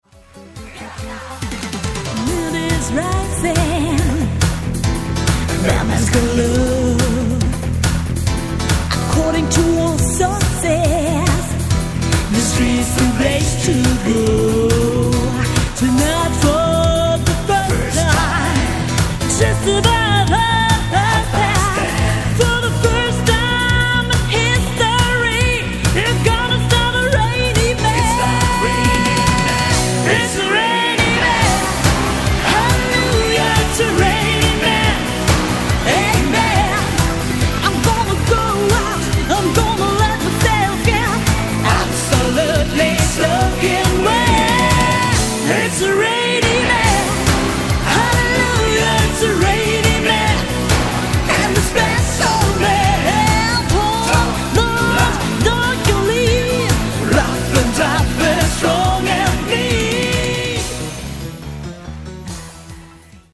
Vocals, Bass, Keys, Trumpet
Guitar, Trombone
Drums